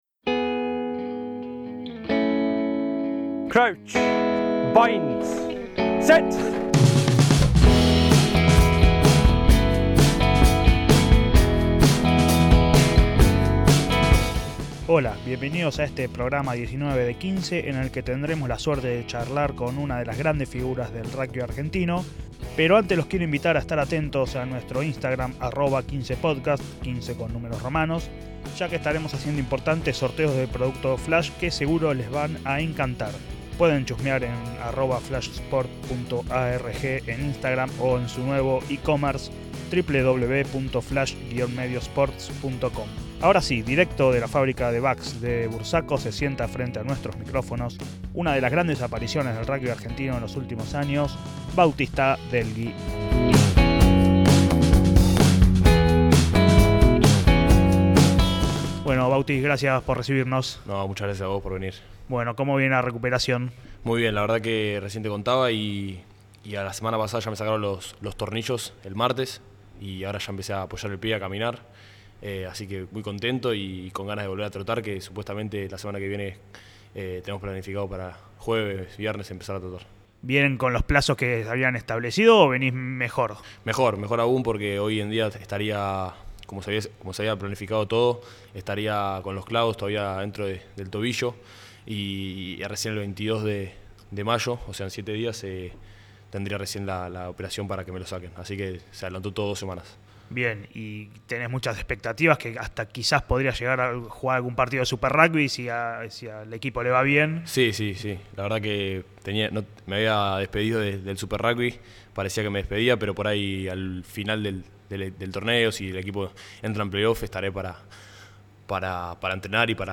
Charlas de rugby con los protagonistas!